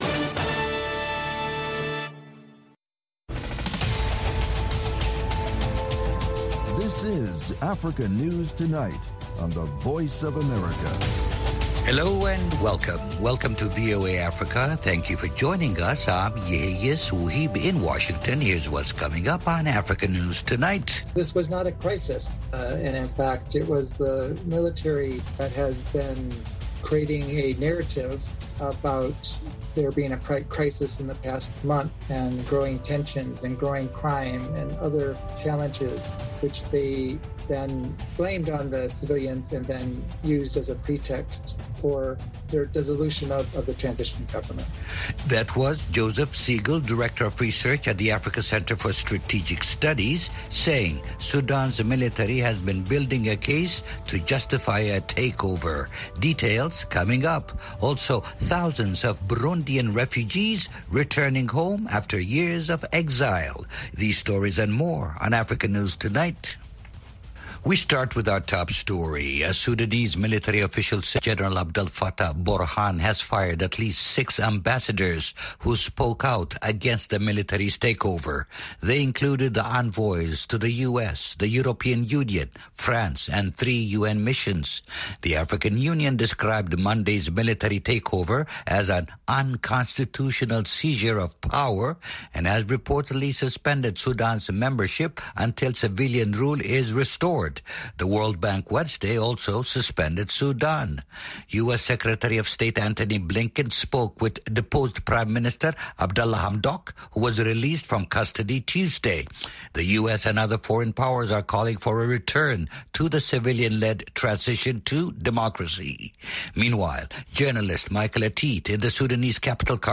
Africa News Tonight is a lively news magazine show featuring VOA correspondent reports, interviews with African officials, opposition leaders, NGOs and human rights activists.
Music and the popular sports segment, Sonny Side of Sports, round out the show.